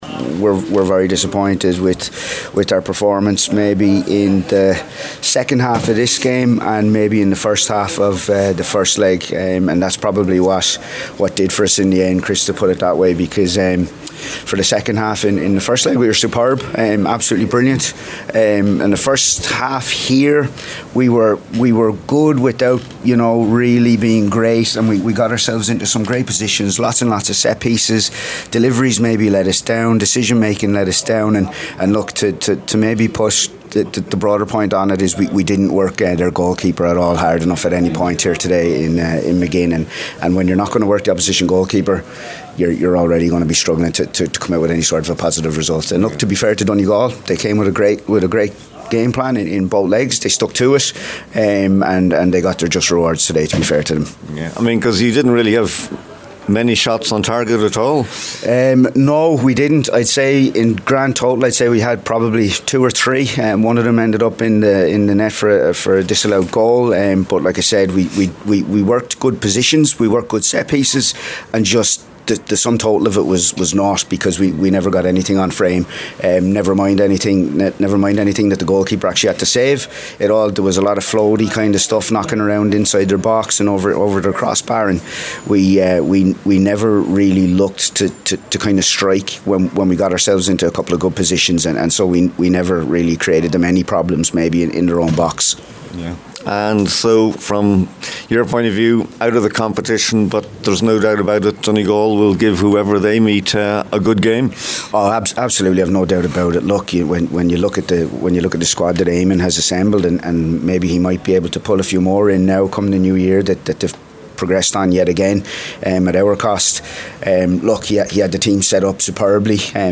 after the match today